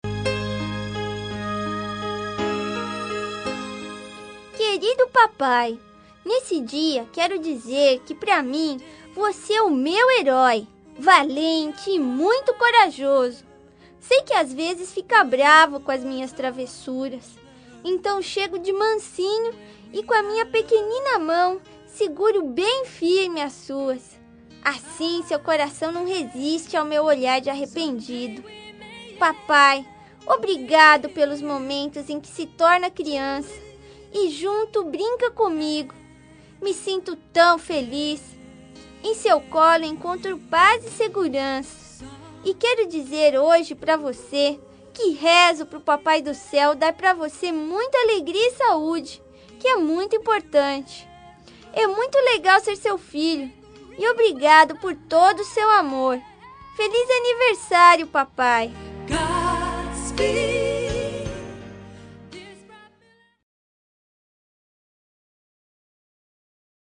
Aniversário Voz Infantil – Pai – Voz Masculina – Cód: 257323